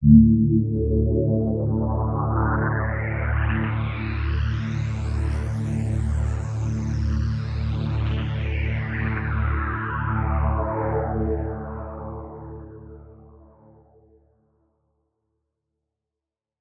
Bass_D_01.wav